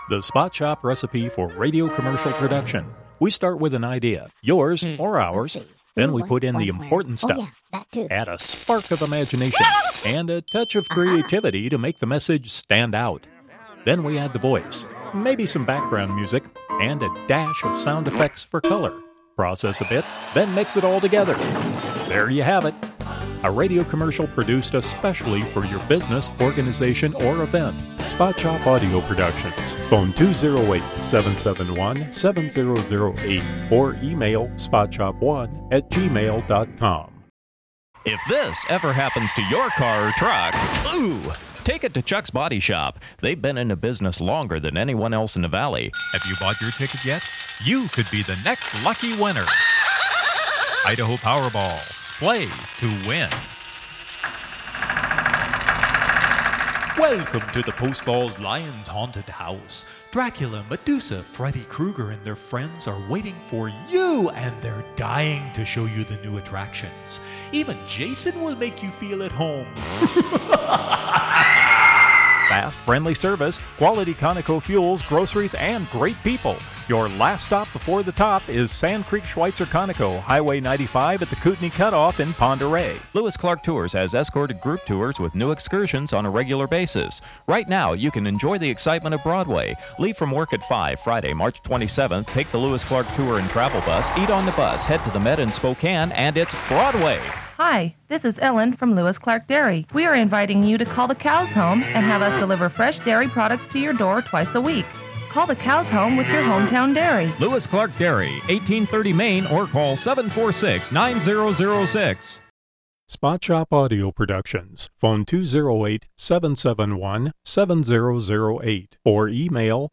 English - USA and Canada
Middle Aged